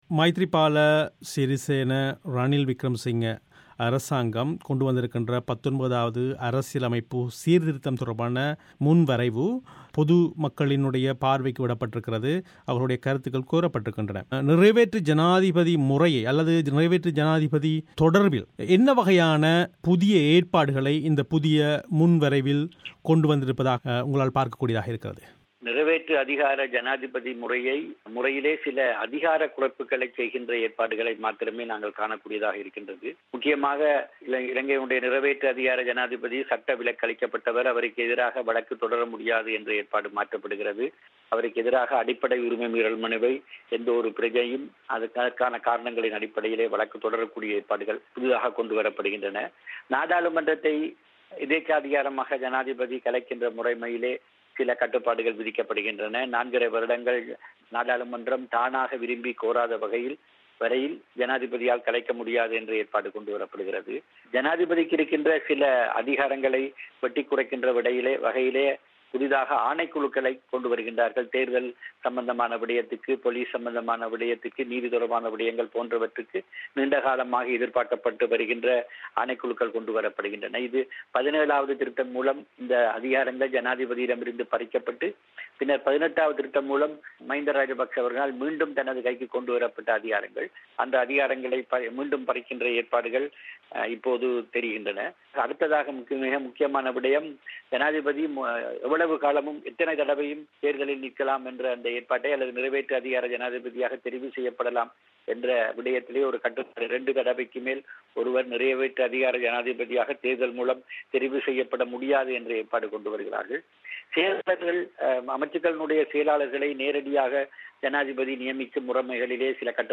ஆய்வுக் கலந்துரையாடலை